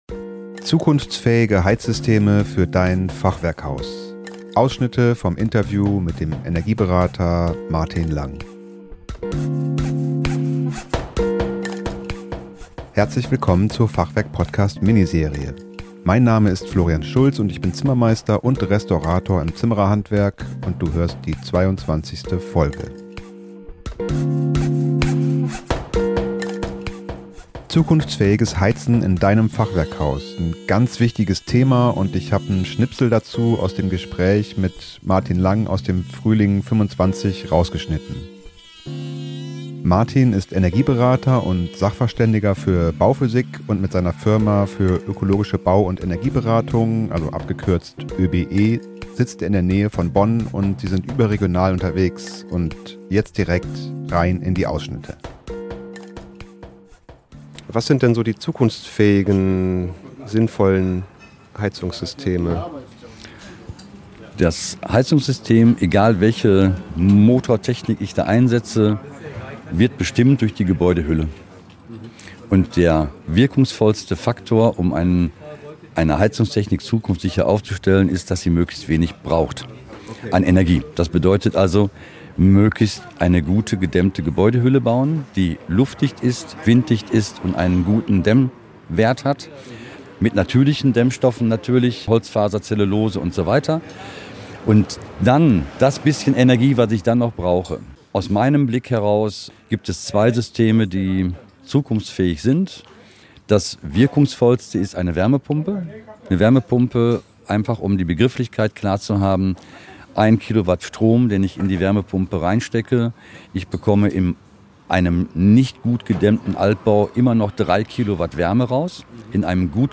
Zukunftsfähige Heizsysteme für Dein Fachwerkhaus - Ausschnitte aus dem Interview